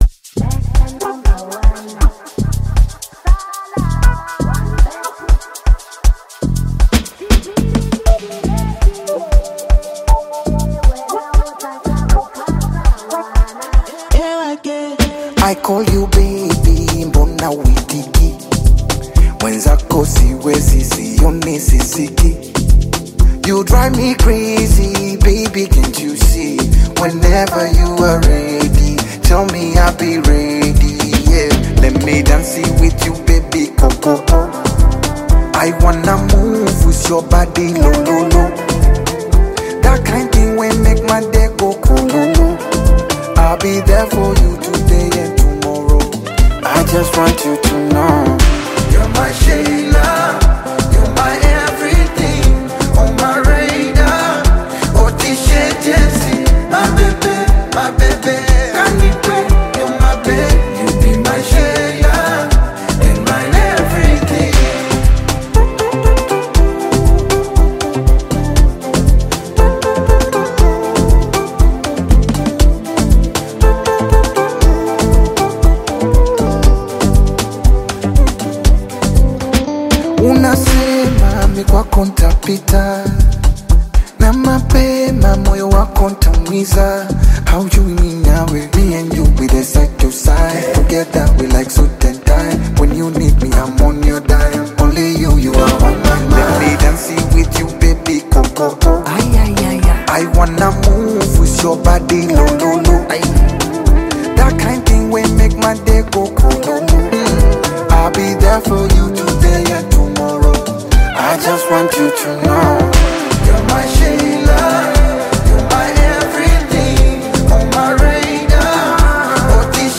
smooth Afro-Pop/Bongo Flava love single
heartfelt vocals, catchy melodies and romantic lyrics
Genre: Afrobeat